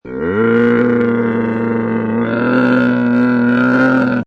Camel 4 Sound Effect Free Download